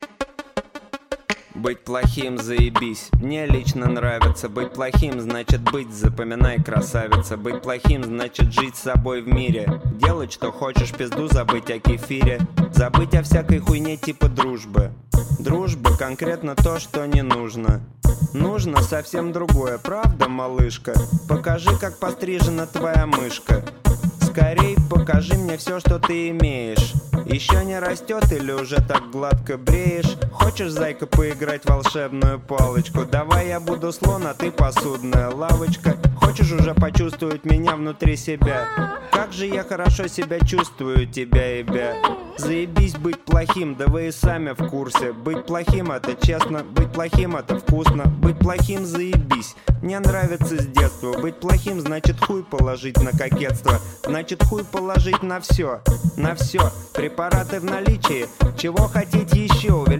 Материал был записан с весны по осень 2007 года в Москве.